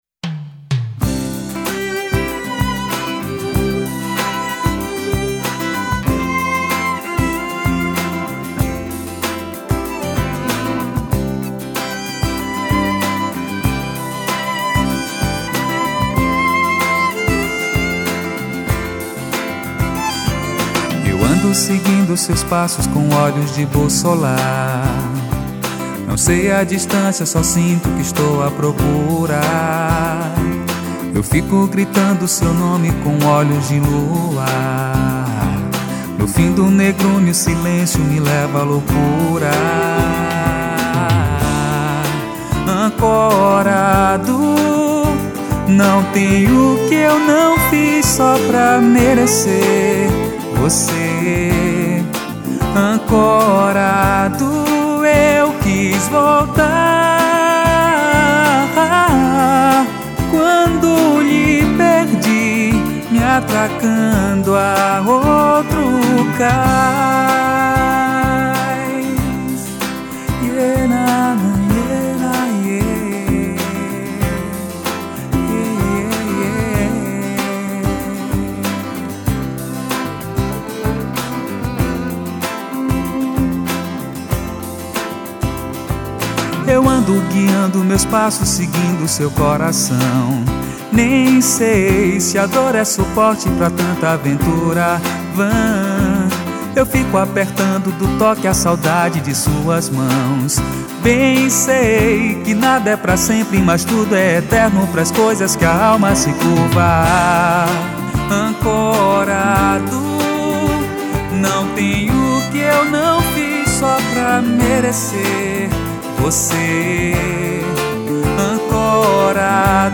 757   05:59:00   Faixa:     Mpb
Pandeiro
Voz, Violao Acústico 6